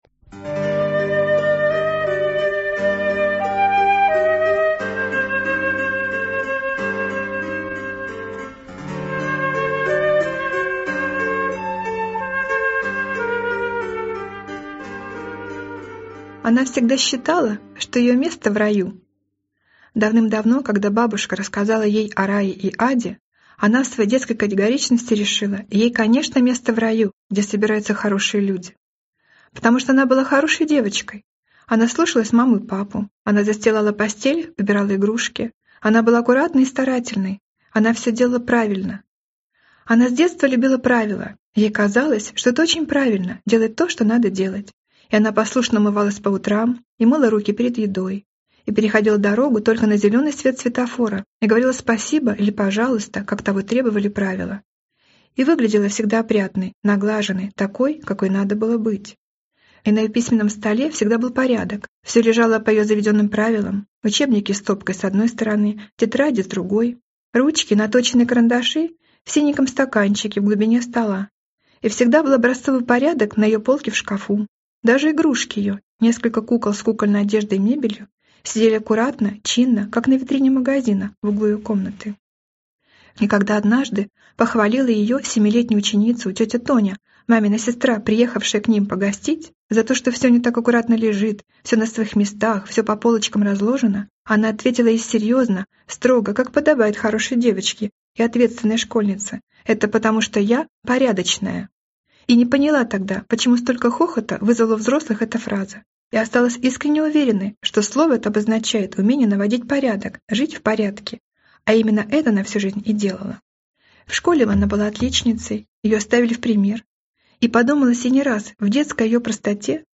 Аудиокнига Рай не место для любви | Библиотека аудиокниг